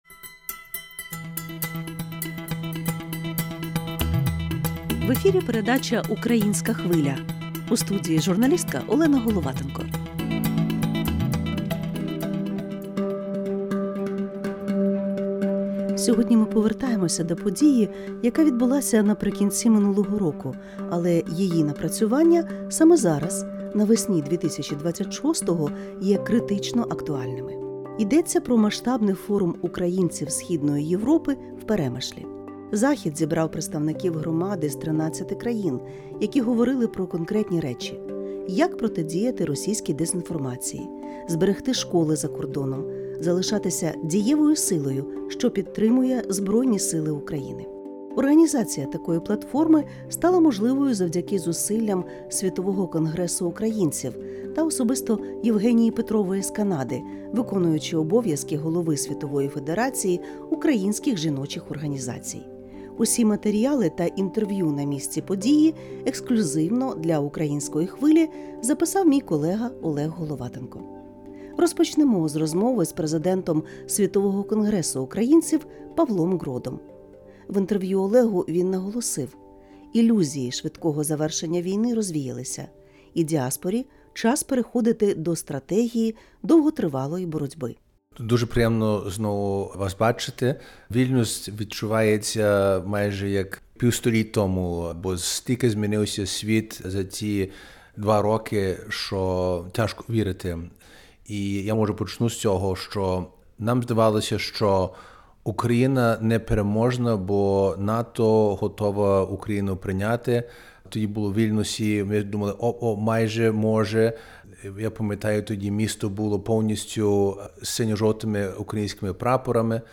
У передачі «Українська Хвиля» на радіо LRT Klasika підбиваємо підсумки масштабного Форуму українців Східної Європи, який об'єднав лідерів громад із 13 країн.